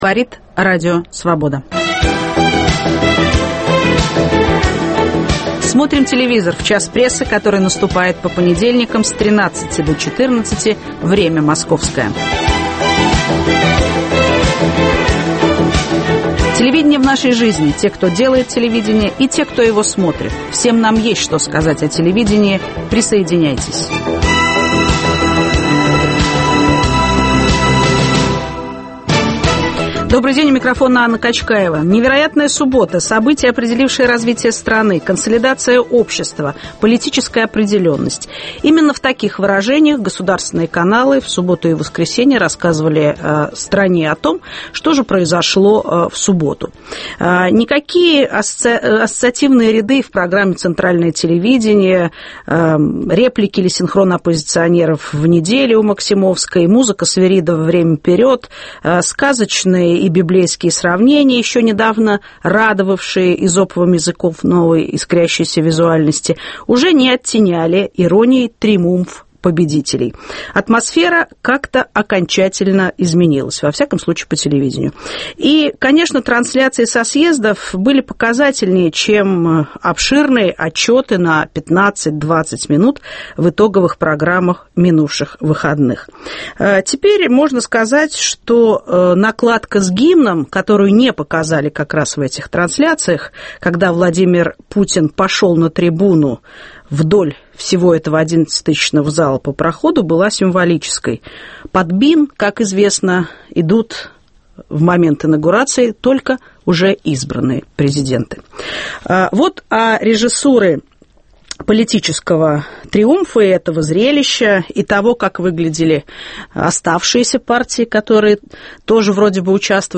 Как телевидение освещало начало третьего срока Путина? В студии